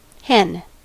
Ääntäminen
IPA: /kɑ.nɑ/